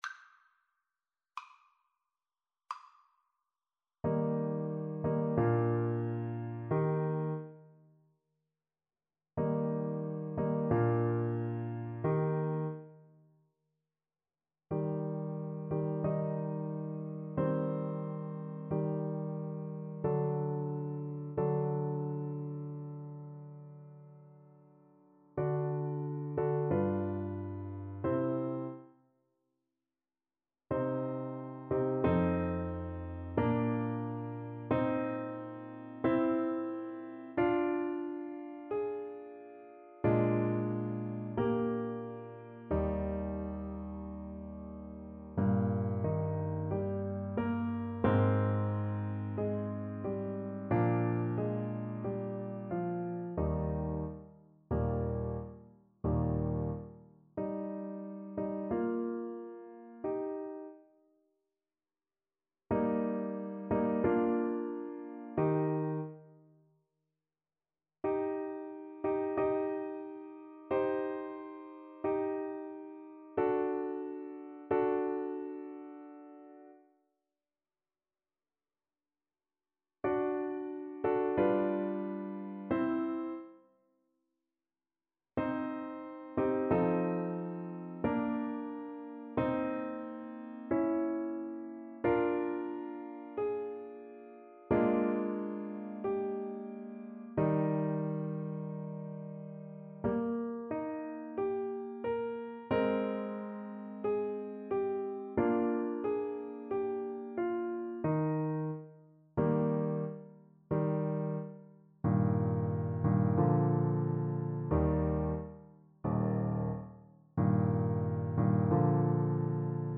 Larghetto =c.45
4/4 (View more 4/4 Music)
Classical (View more Classical Trumpet Music)